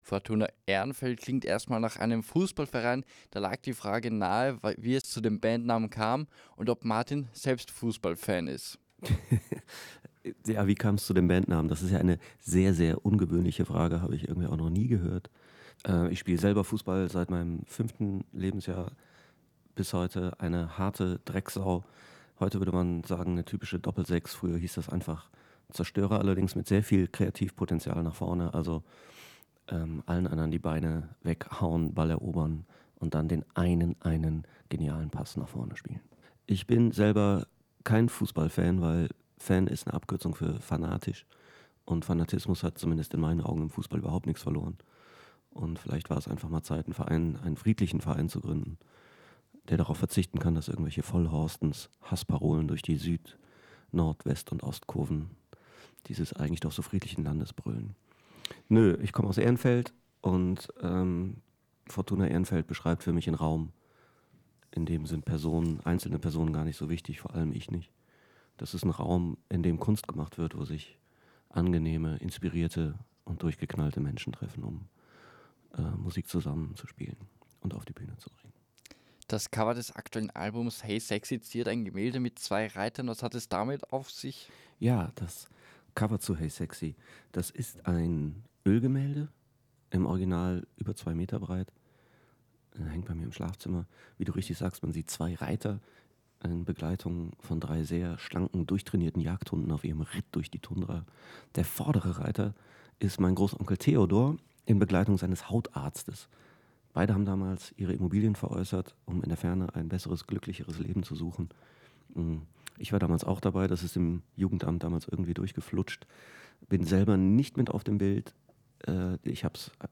Auch 2018 haben unsere Musikredakteure Möglichkeiten wahrgenommen, Künstler bei uns im Studio zu begrüßen oder vor Konzertauftritten vor Ort zu interviewen.
Do, 15.02.2018 – Interview im Weckruf vor dem Konzert in Helgas Stadtpalast